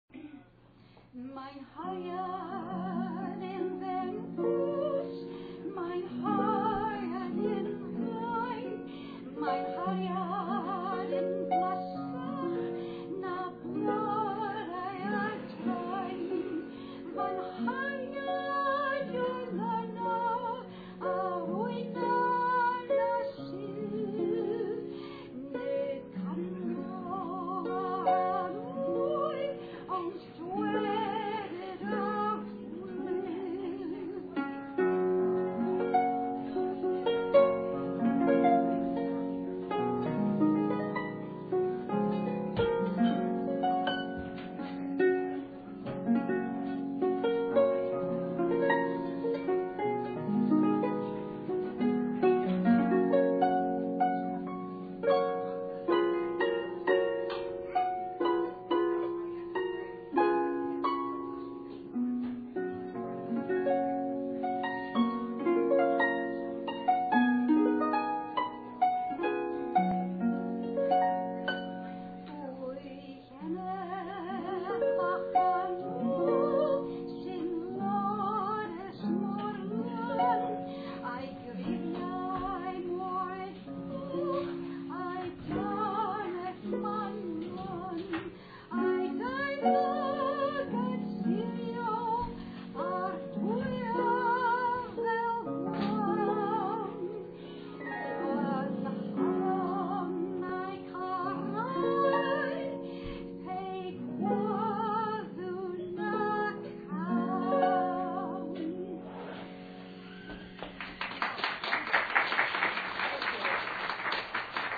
Classical Music Festival | February 11, 2007 | Blue Frog Coffeehouse| Cortland, NY USA
Mae 'Nghariad i'n Fennws (My Dear One is Slender) [Traditional Welsh
harp/voice